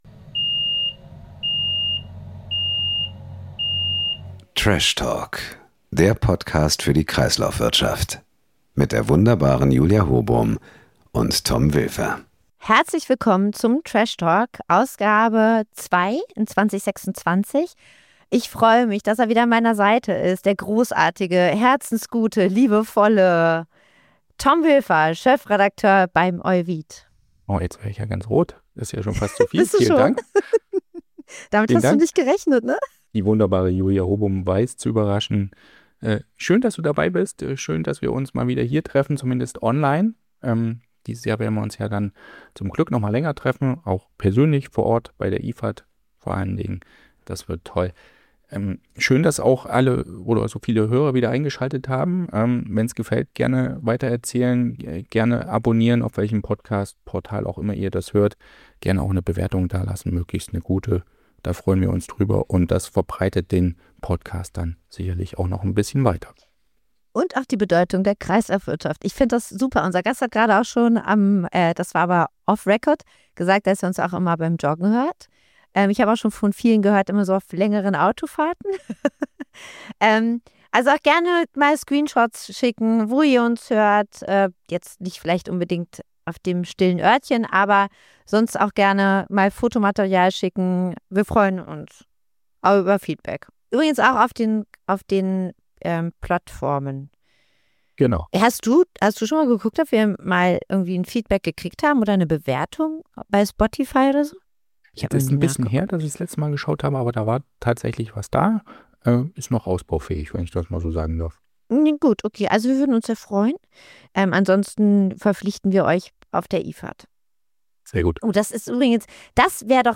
Die beiden TrashTalker beleuchten unterschiedliche Gutachten, diskutieren die Idee einer gemeinsamen Herstellerstelle und schauen auf offene Punkte im angekündigten Eckpunktepapier des Bundesumweltministeriums.